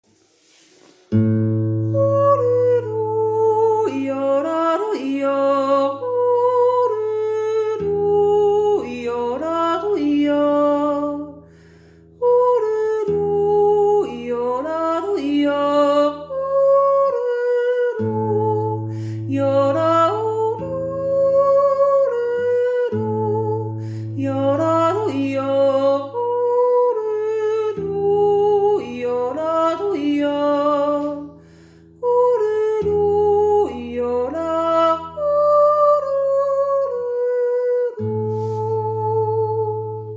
bergchilbi-jutz-1.mp3